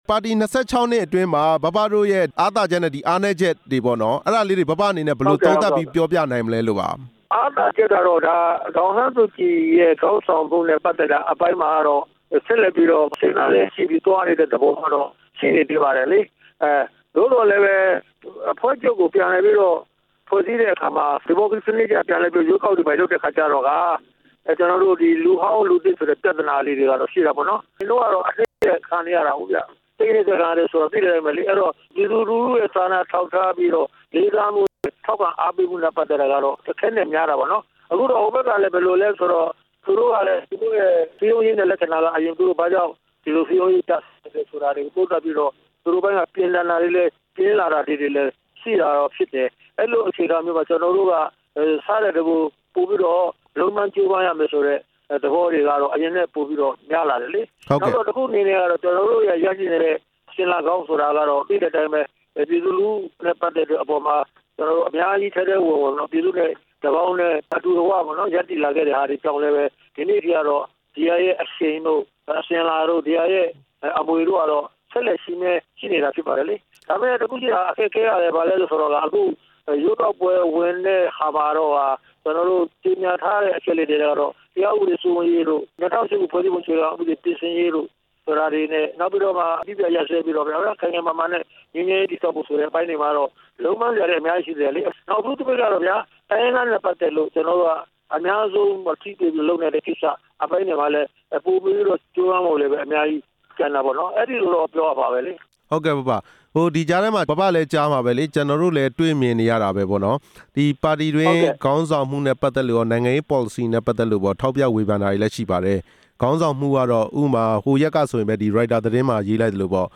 ဦးတင်ဦးနဲ့ မေးမြန်းချက်